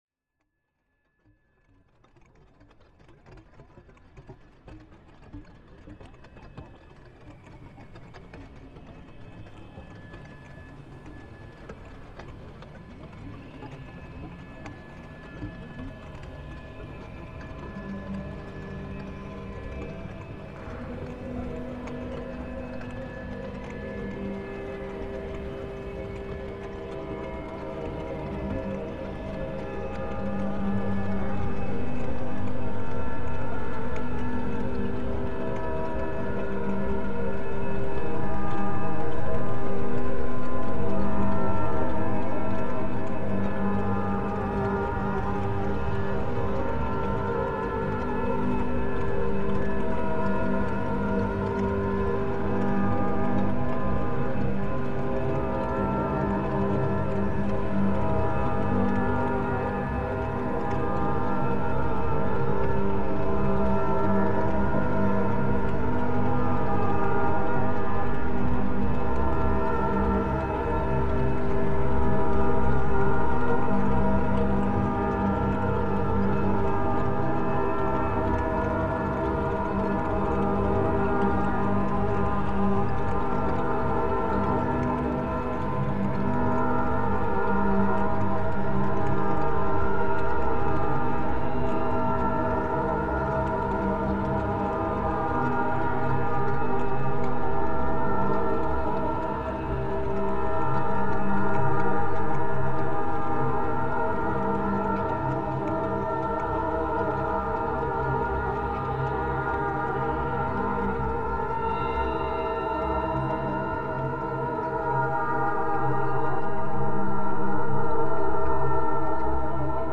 multiple artists create a new composition using the same original field recording as source material and inspiration